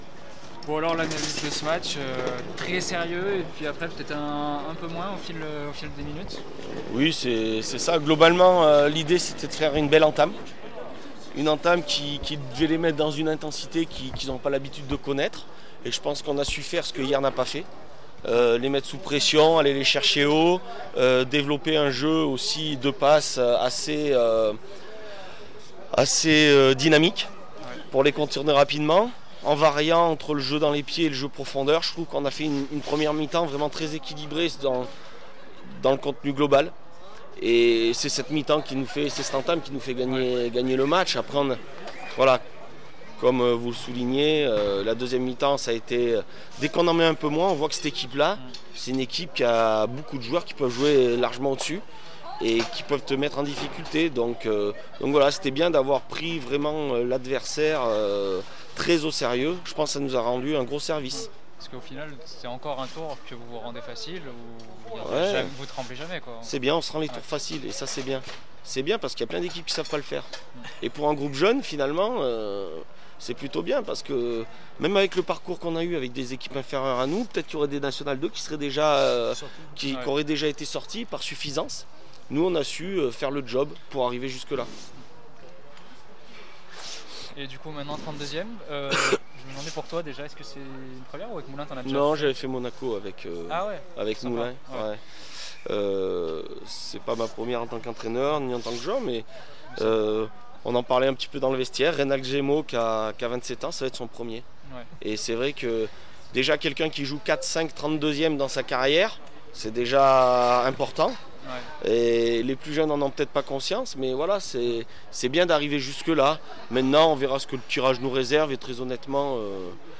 14 décembre 2023   1 - Sport, 1 - Vos interviews
coupe de France Chaponnay marennes 0-3 le puy foot 43 réaction après match